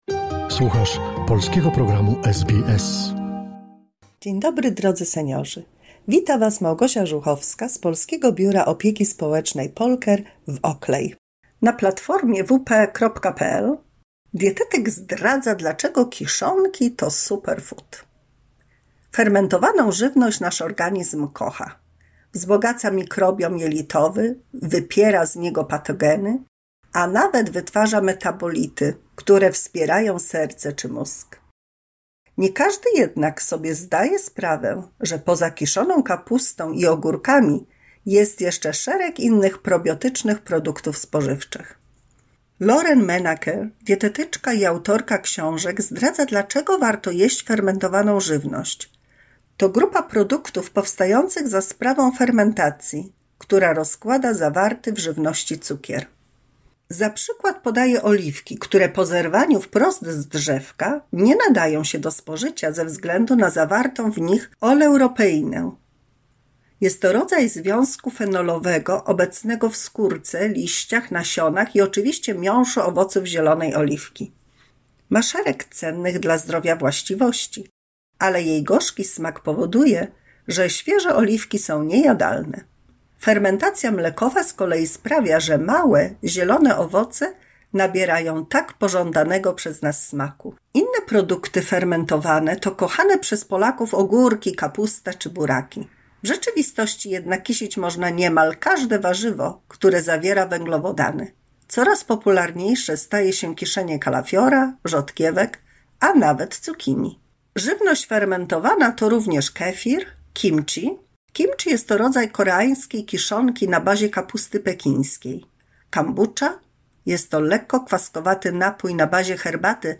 149 mini słuchowisko dla polskich seniorów